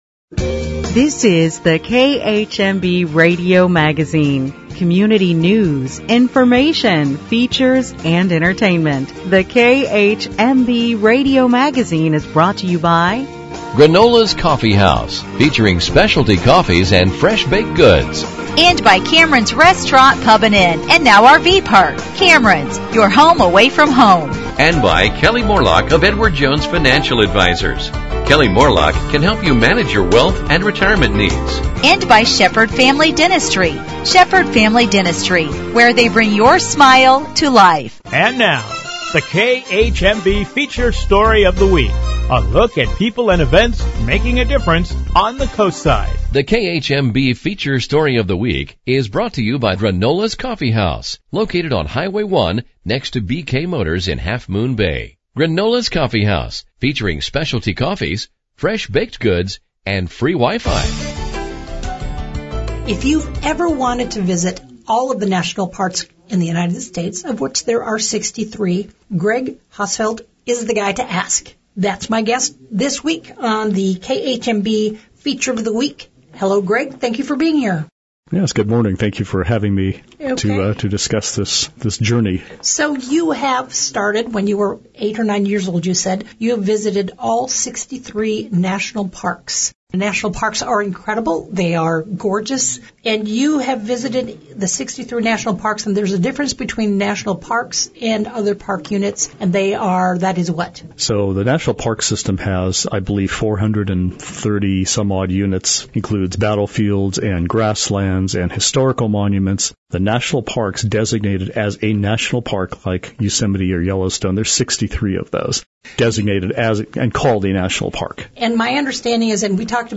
Each week in our Feature Segment the station will highlight an individual making a difference in our community or news story of importance. In addition there will be a traditional newscast with various stories of interest.